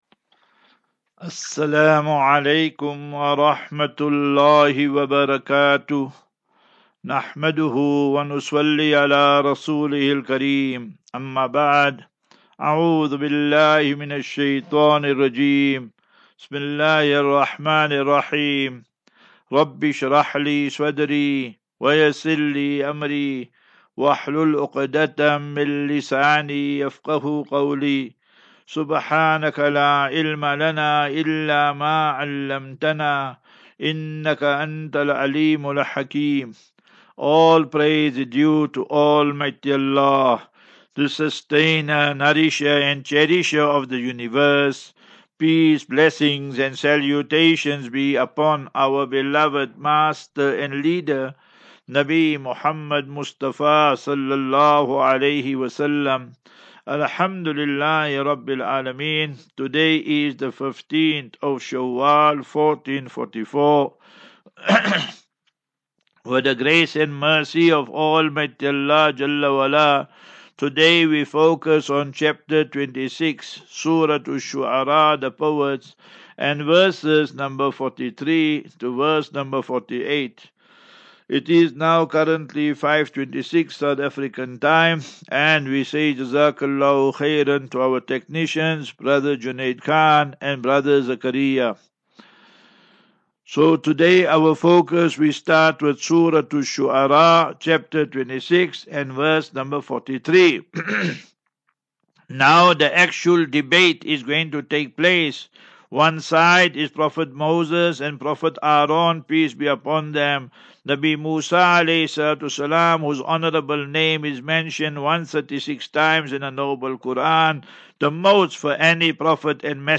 View Promo Continue Install As Safinatu Ilal Jannah Naseeha and Q and A 6 May 06 May 23 Assafinatu Illal